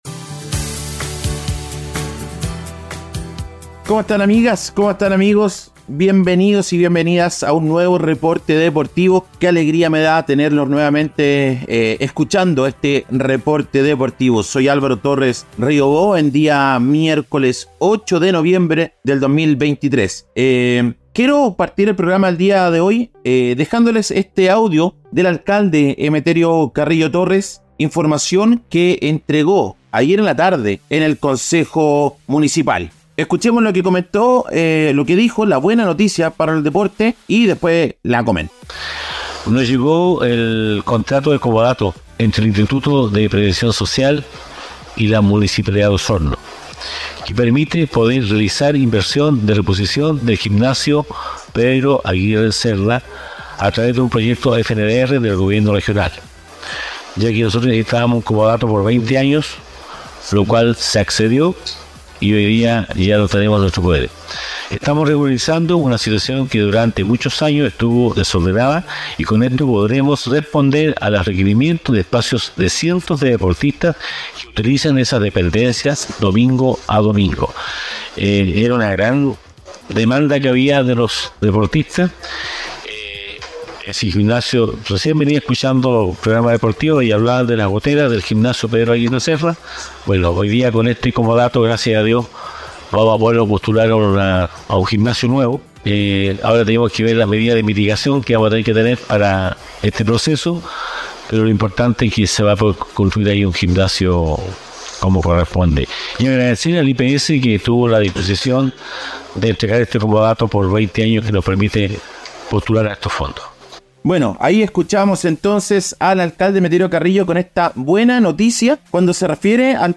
En este episodio, les traemos un breve reporte con las "deportivas" más destacadas de las últimas 24 horas.